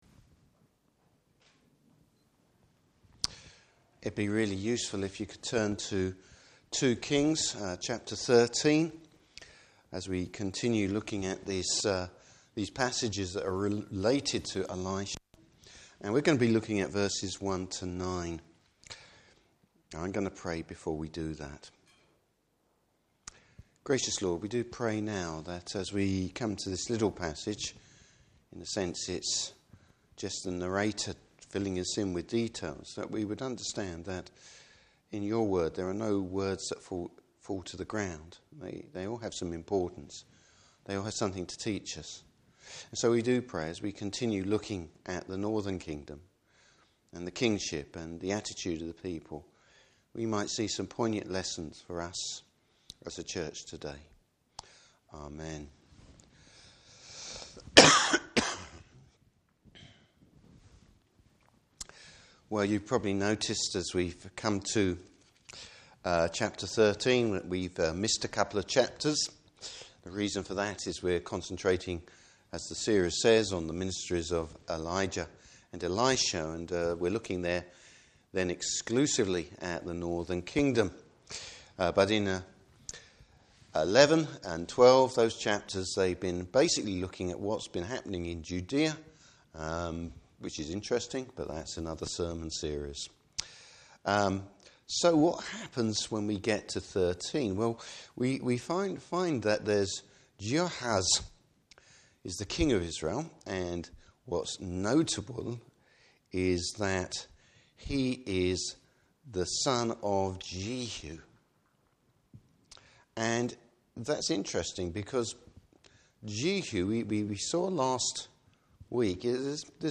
Service Type: Evening Service Bible Text: 2 Kings: 13:1-9.